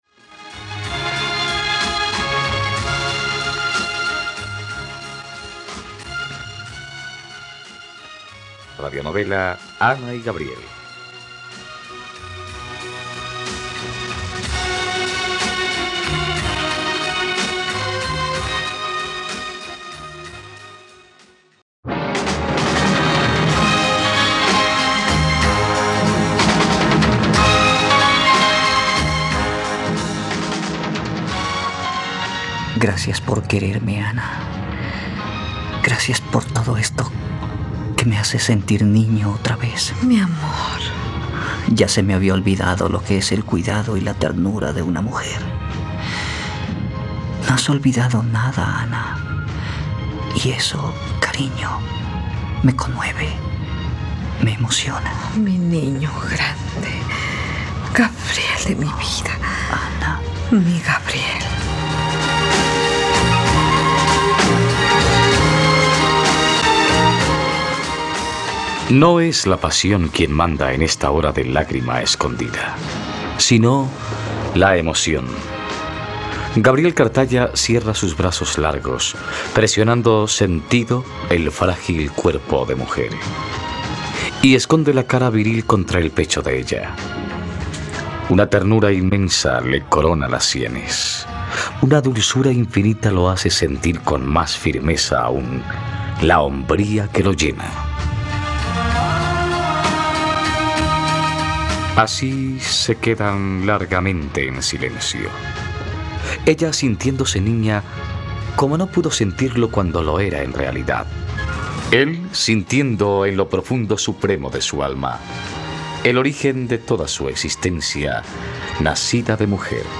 ..Radionovela. Escucha ahora el capítulo 116 de la historia de amor de Ana y Gabriel en la plataforma de streaming de los colombianos: RTVCPlay.